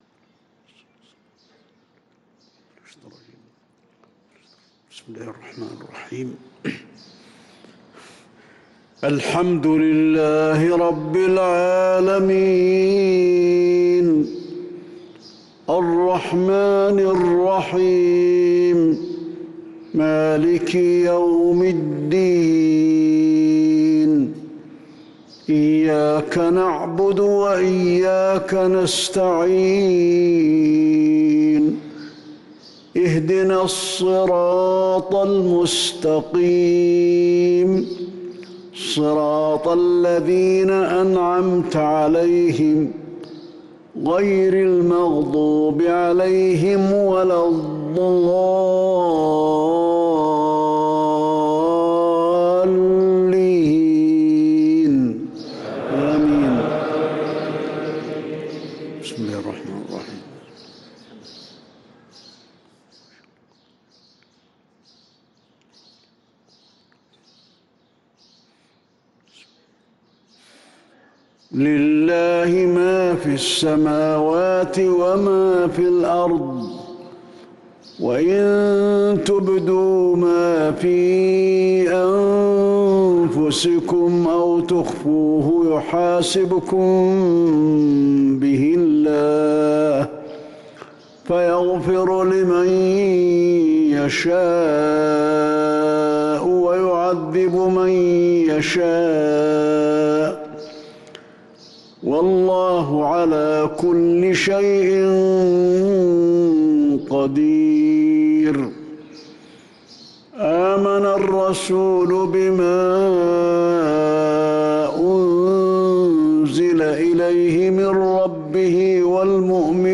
صلاة المغرب للقارئ علي الحذيفي 30 جمادي الآخر 1445 هـ